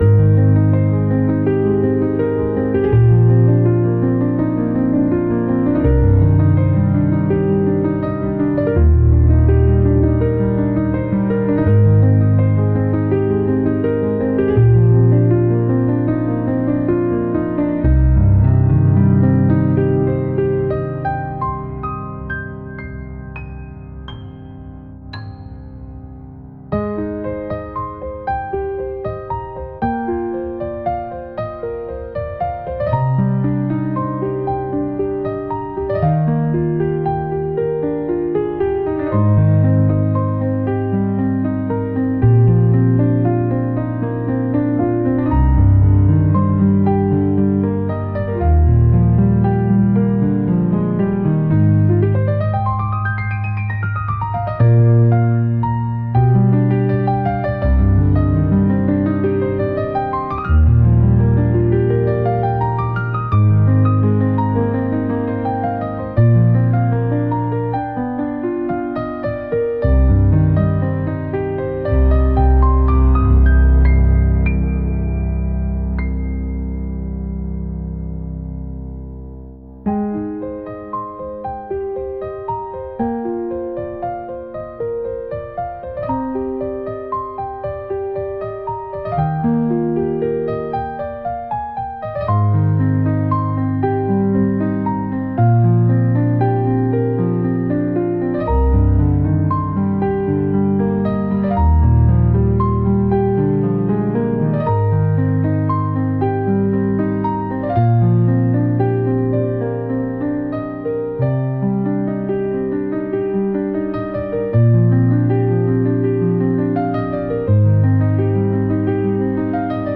AIの力を借りて、美しい風景画像とともにリラックスできるBGMをお届けしているYouTubeチャンネルです。